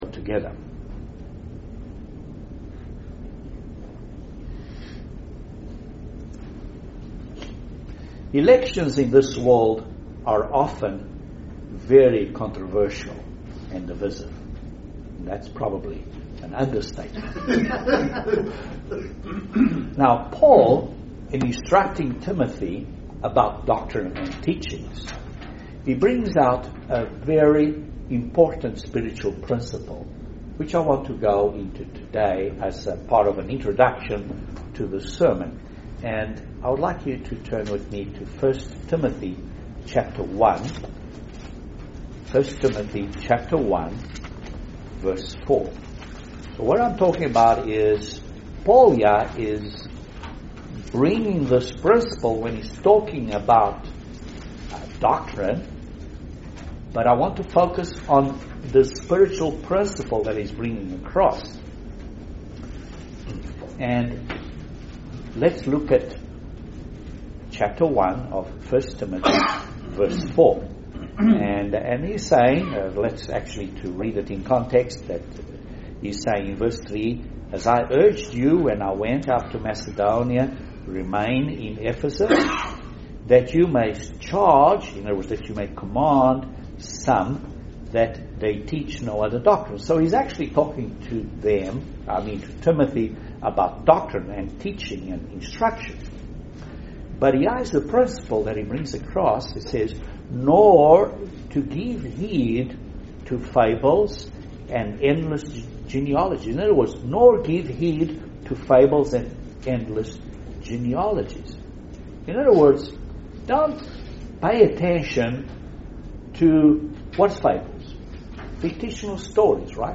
We are God's chosen people and this sermon reminds us of how we must discern between good and evil , and persevere until the end.